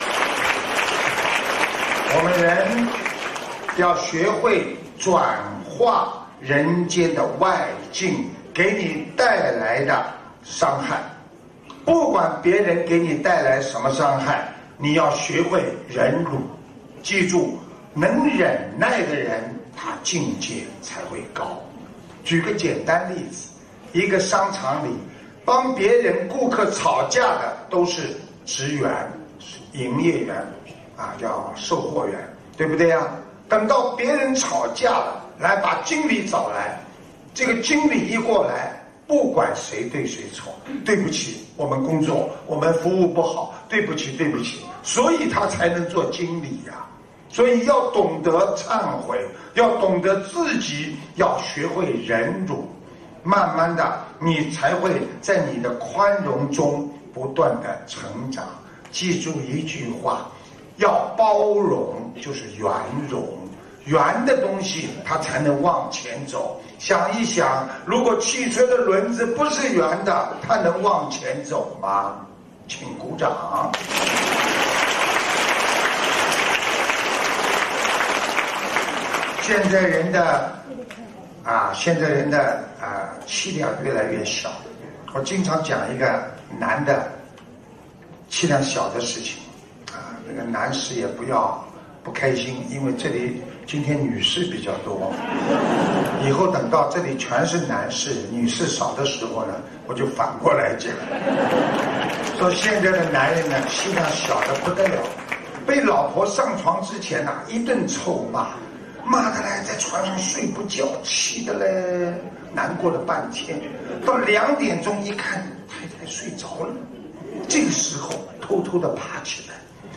西班牙·马德里法会开示